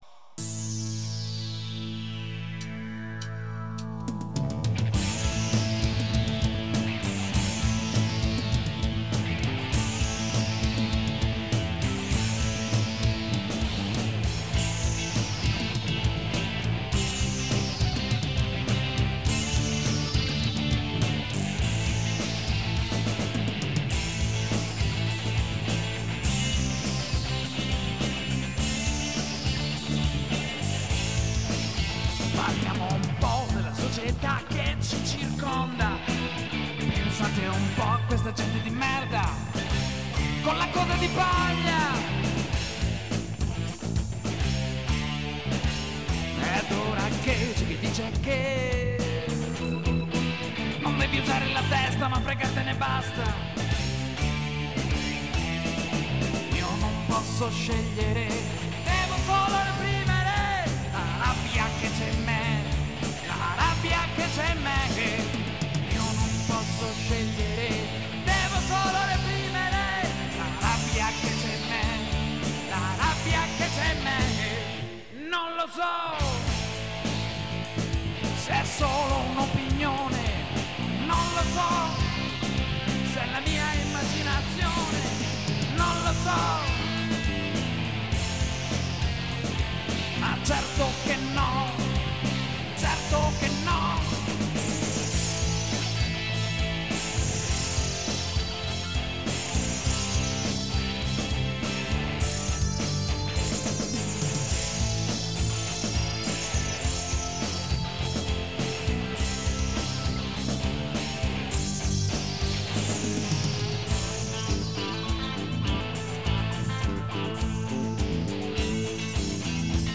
GenereRock